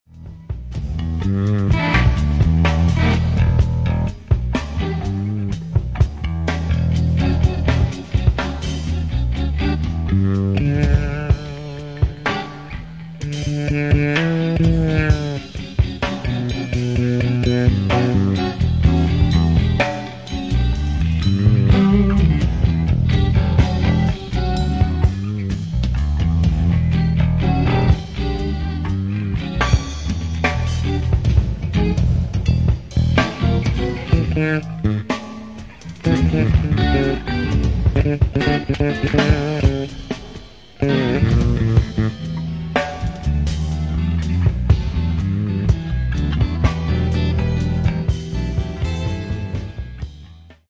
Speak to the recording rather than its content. Converted and HGT Epoxied Fender Jazz style w/ rosewood fretboard recorded direct to board with bridge pickup, tone control about halfway, GHS 45-100 roundwounds (close action)